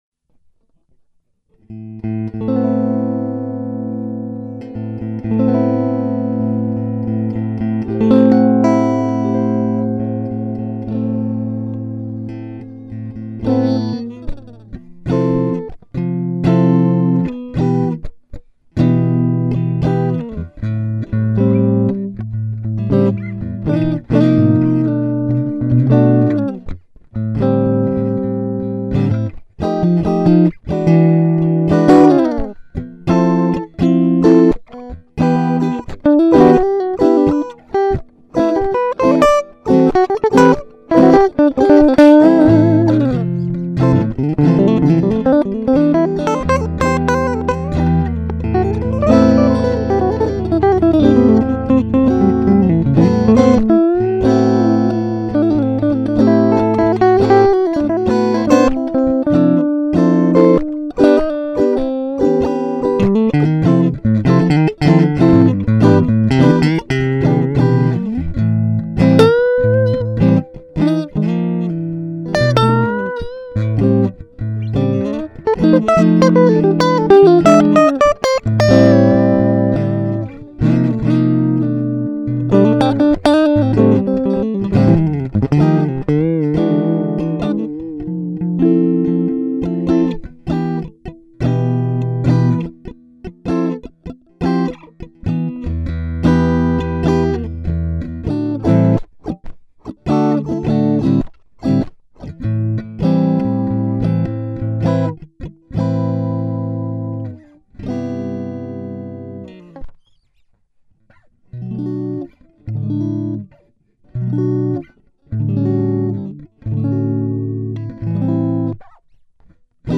new_2025 recording test for focusrite tmp0 tmp10 GRIPP 07 BOSS MEDIA 5 sav14 dorian3 power3 fok6 EL5 MP3 Player!! holds90 sched - Dont delete or change holds85 voicer1 Voice Recorder